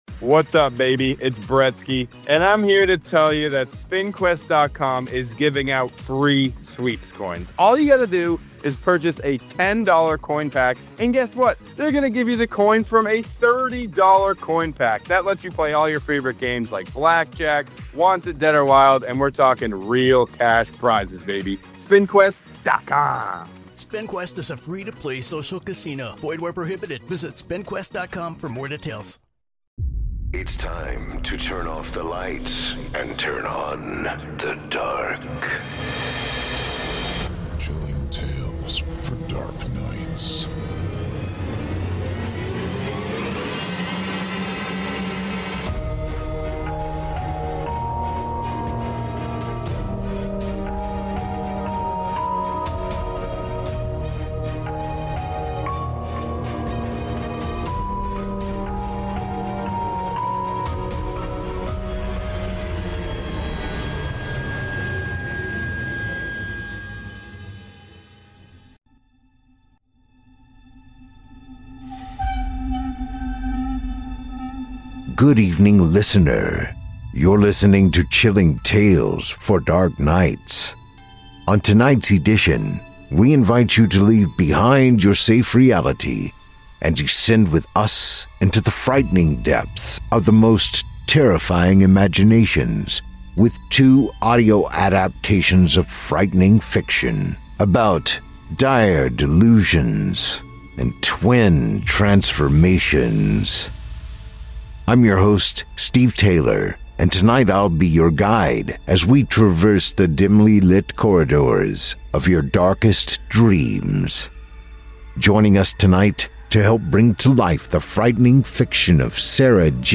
On tonight’s edition, we invite you to leave behind your safe reality, and descend with us into the frightening depths of the most terrifying imaginations, with two audio adaptations of frightening fiction, about dire delusions and twin transformations.